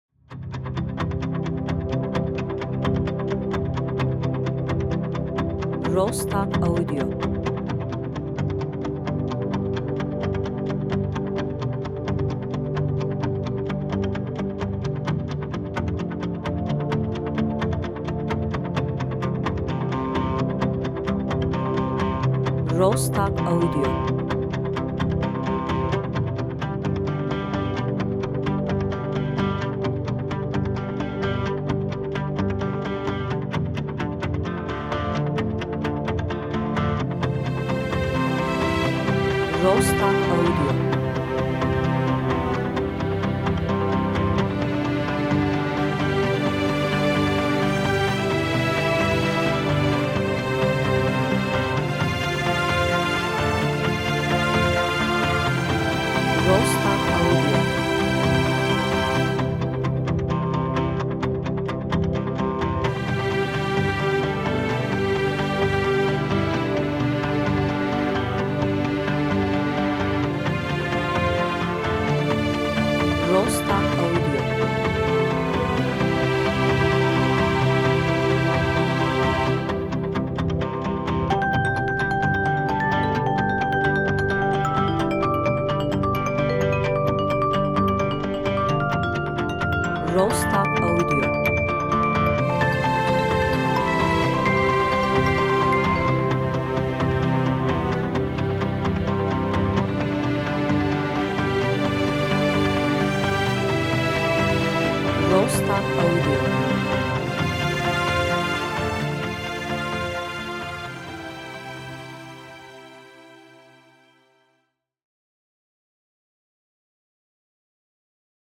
enstrümantal müzik music epik duygusal epic